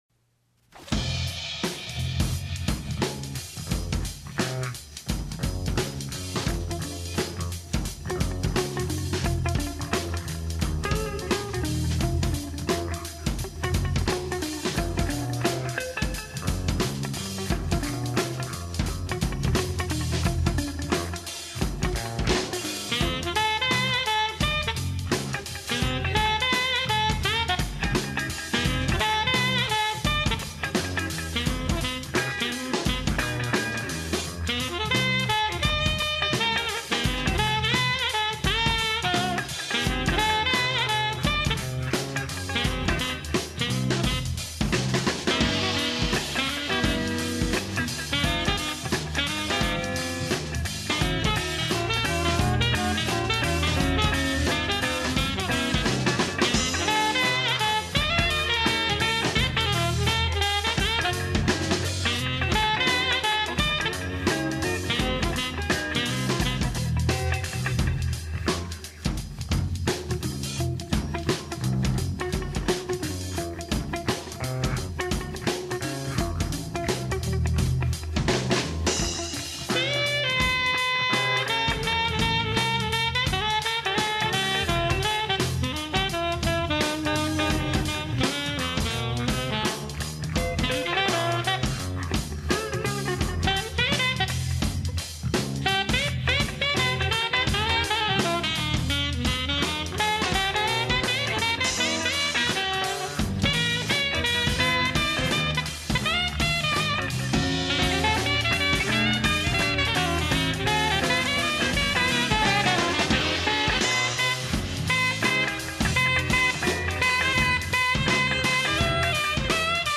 Electric Bassist
live in the studio